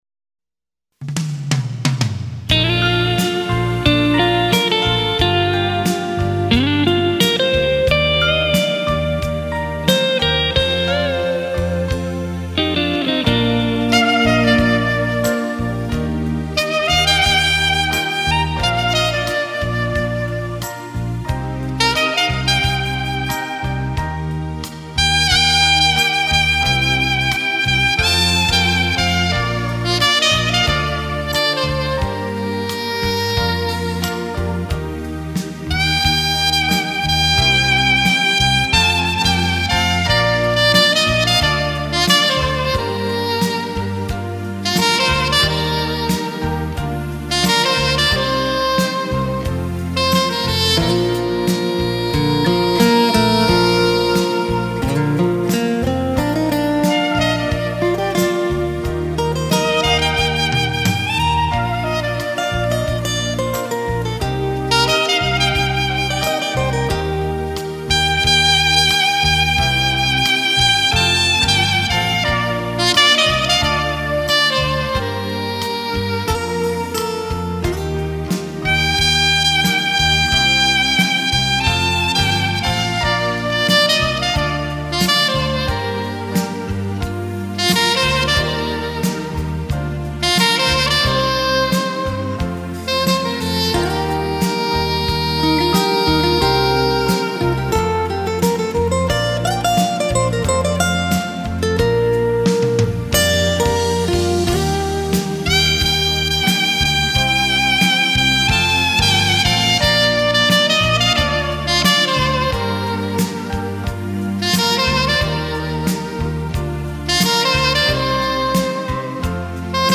喜欢萨克斯妩媚的音色感觉很飘然...